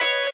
guitar_018.ogg